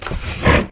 MISC Wood, Foot Scrape 06, Scratch, Heavy.wav